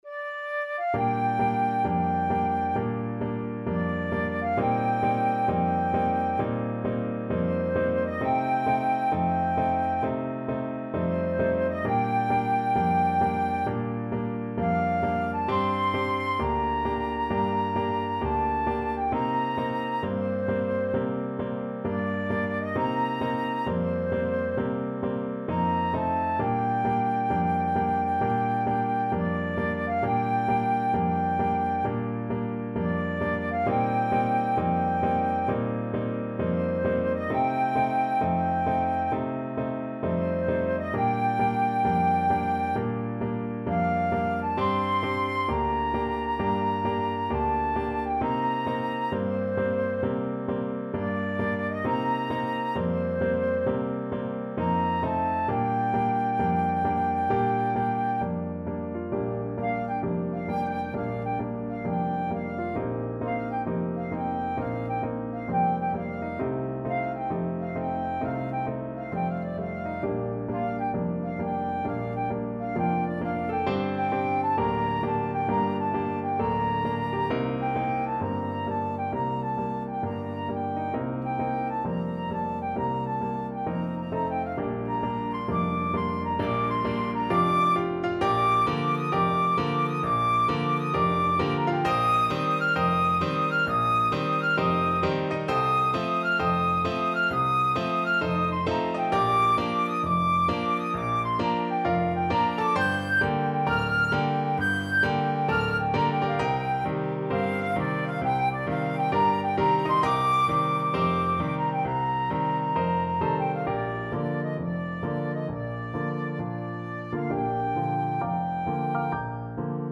Blues Tempo (=66)
Jazz (View more Jazz Flute Music)